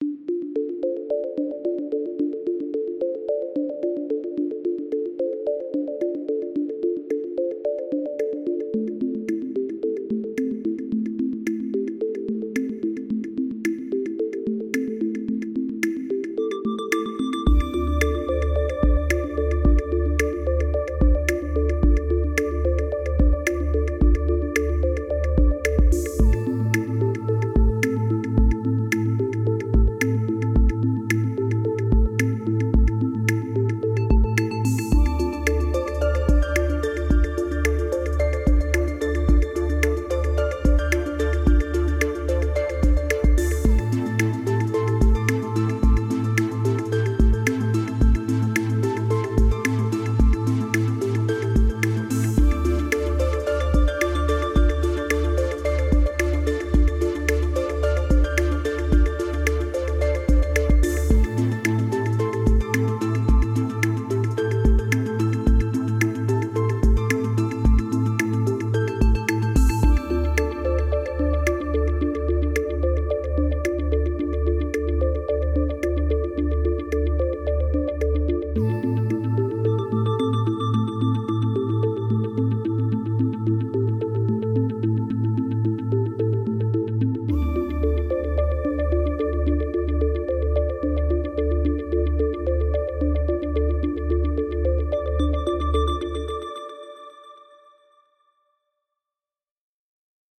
space_chillout.mp3